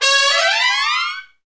Index of /90_sSampleCDs/Roland LCDP06 Brass Sections/BRS_Tpts FX menu/BRS_Tps FX menu